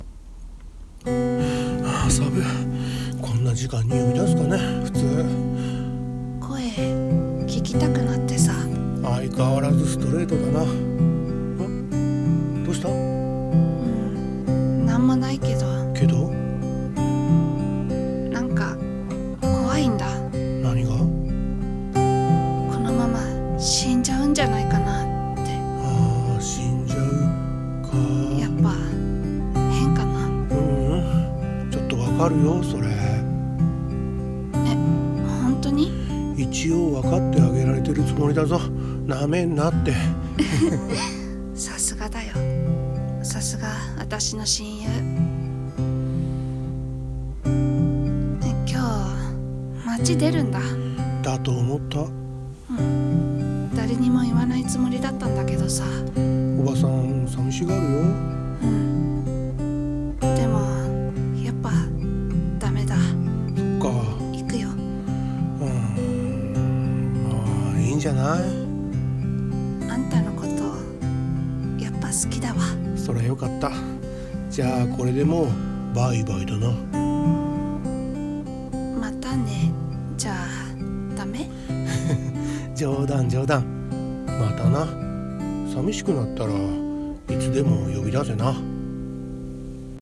【声劇】26時の自由論